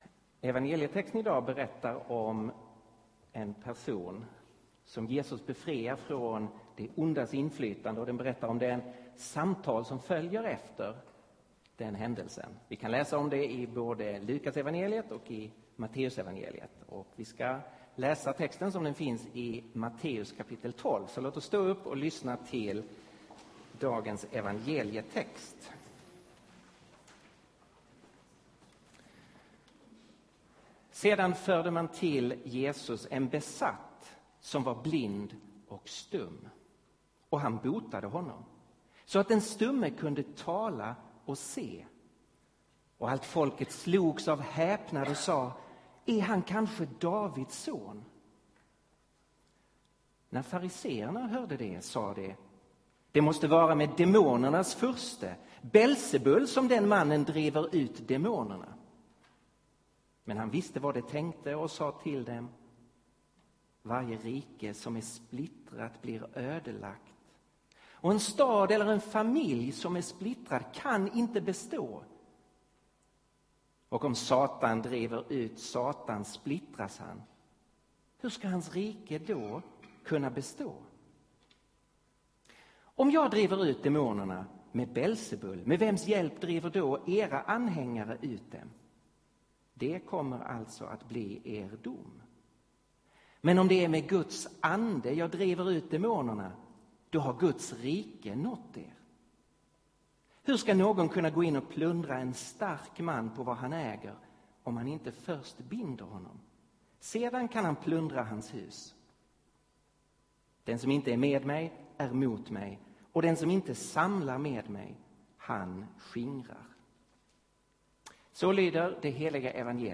Inspelad vid gudstjänst i Betlehemskyrkan i Stockholm 2012-03-11.